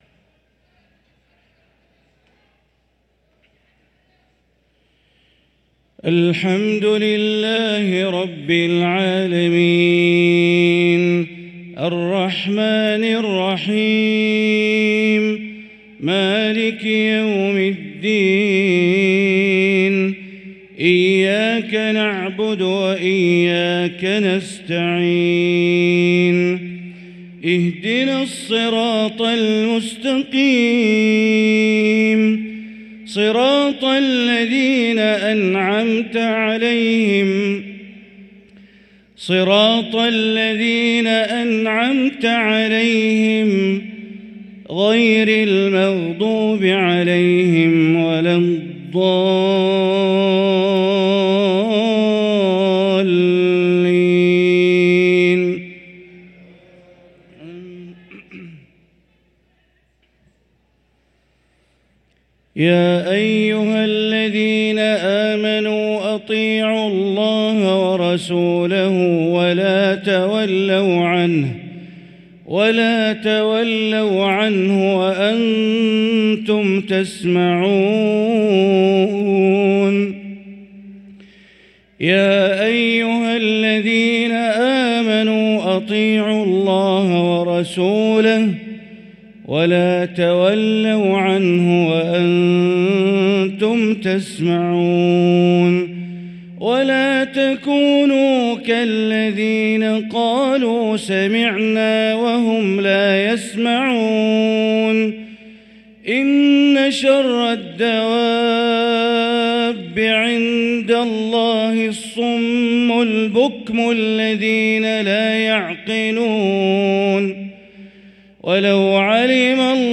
صلاة العشاء للقارئ بندر بليلة 14 رجب 1445 هـ
تِلَاوَات الْحَرَمَيْن .